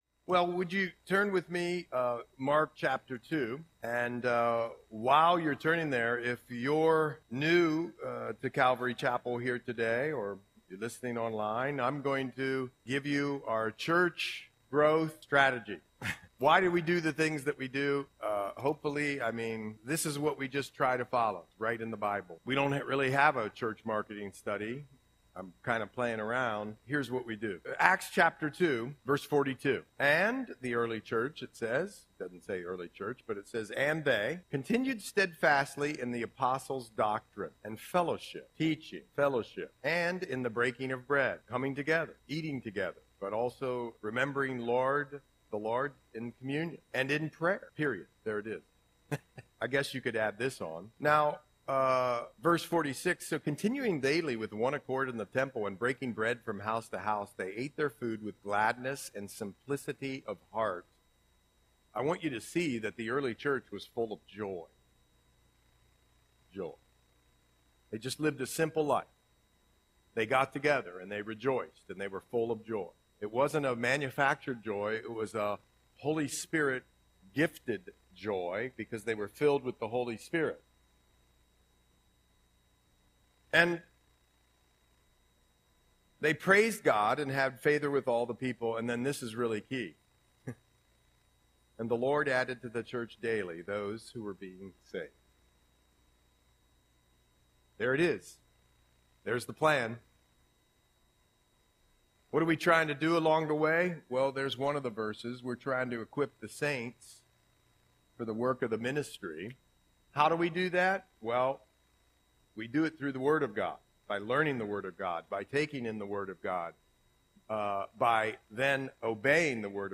Audio Sermon - November 10, 2024